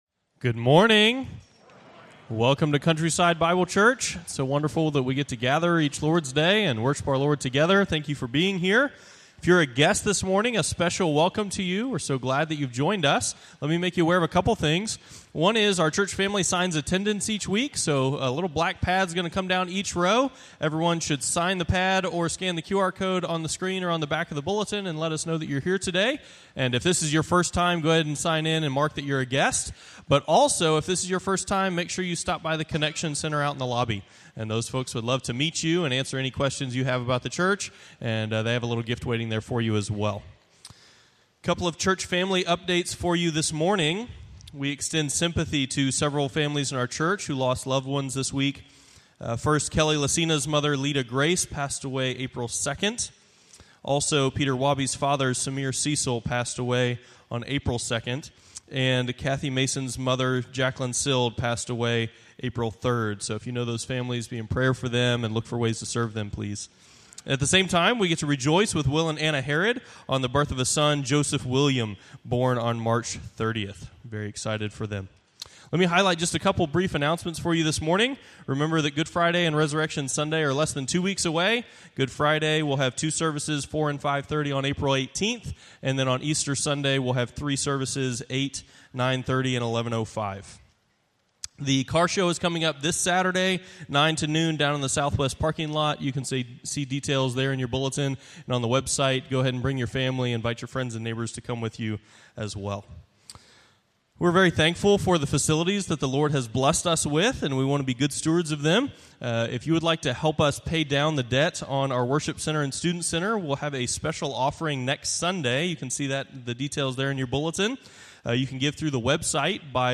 Morning Worship Service